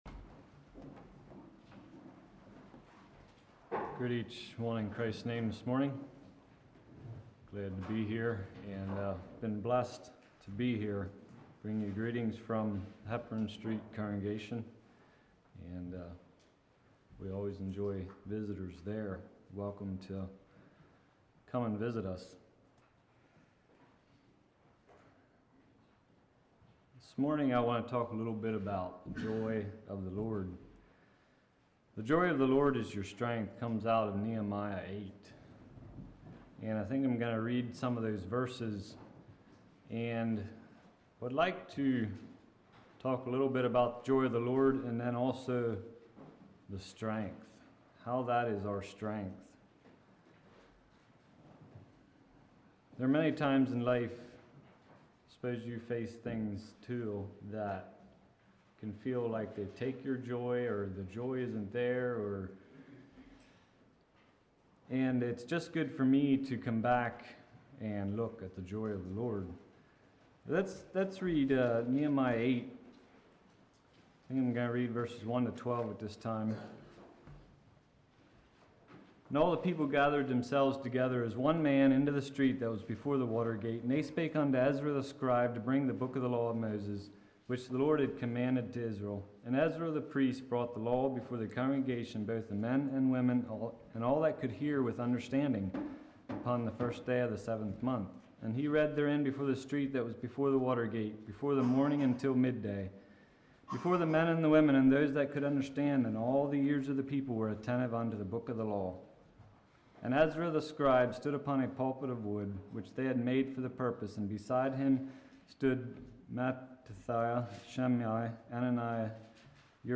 31:47 Summary: Teaching on the joy we find in serving the Lord.